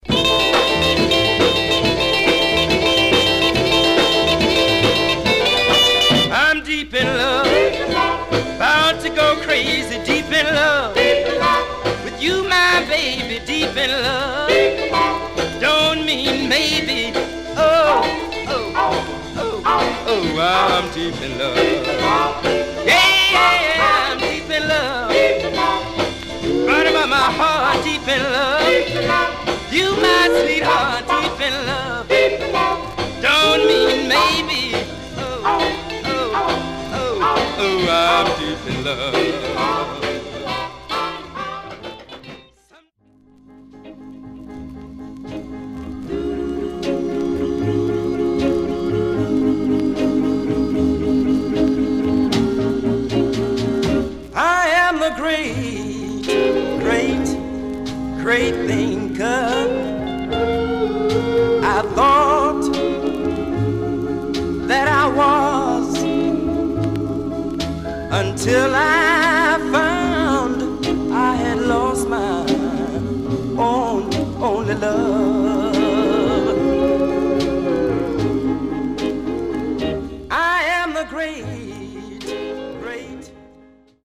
Stereo/mono Mono
Teen